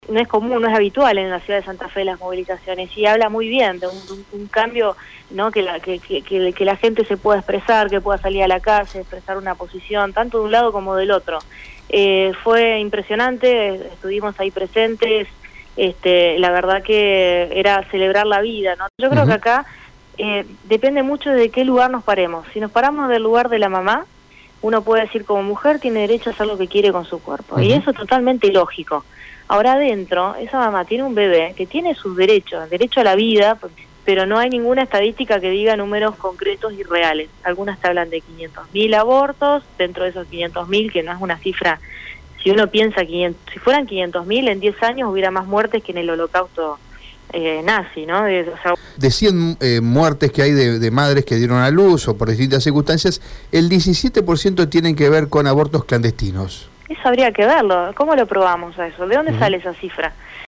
La diputada Nacional por Santa Fe, Lucila Lehmann (Cambiemos) habló este lunes con Radio EME, sobre la marcha » a favor de la vida» que se realizó con gran convocatoria en la Capital provincial y otros puntos del país.